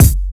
CC - Crew Luv Kick.wav